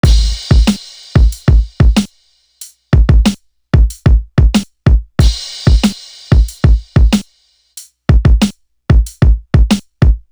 Latin Thug Drum.wav